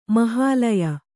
♪ mahālaya